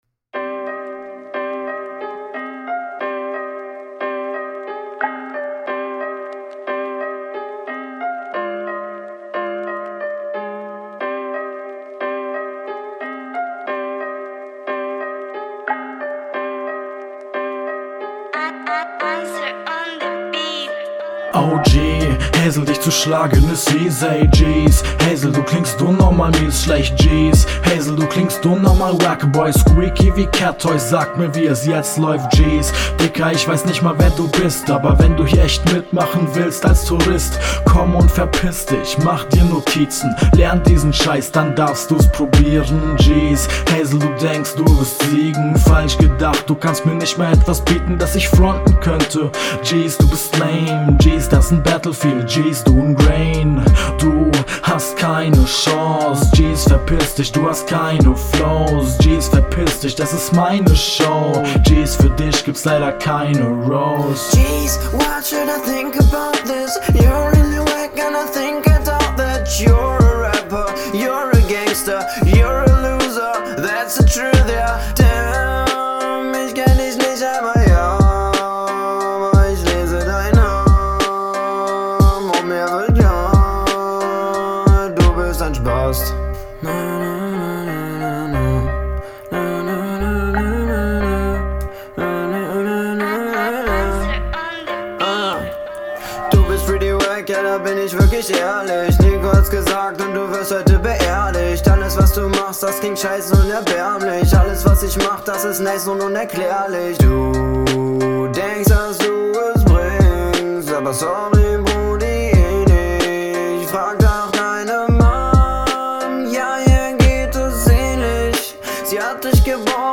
Battle Rap Bunker